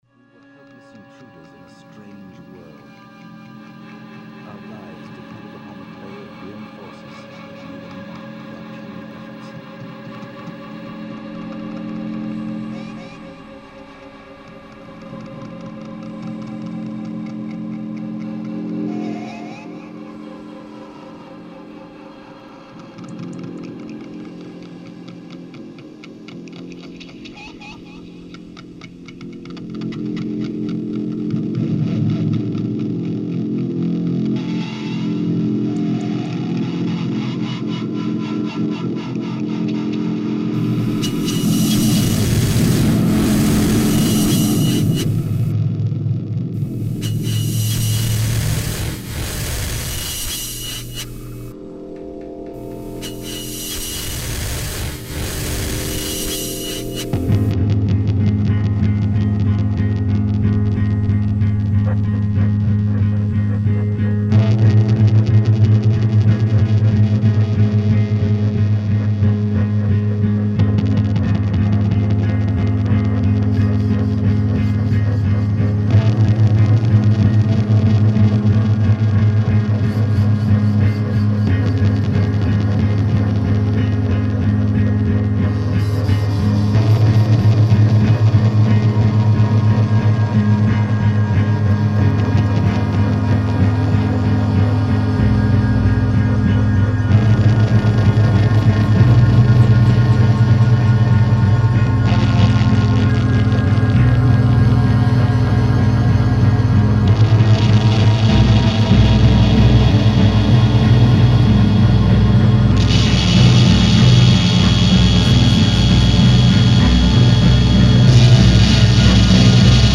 at the Attic Observation Deck
Denver's most infamous duo